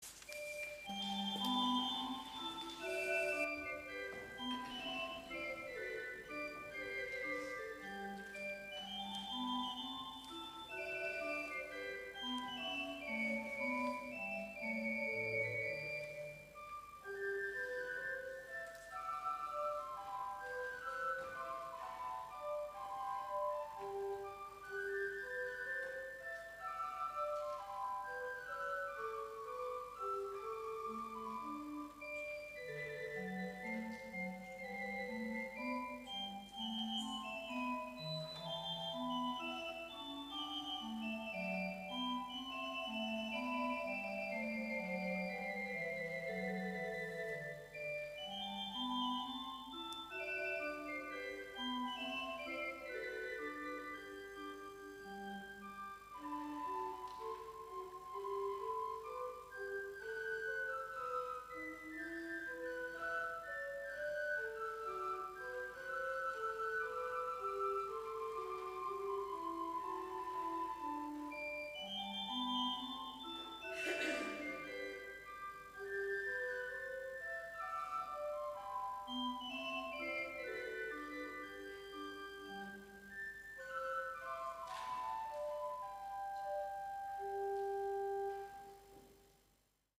Bewerkingen voor Orgel + Fluit
Vogelstukje (eigen werkje / opname tijdens kerkdienst 7/8/2022)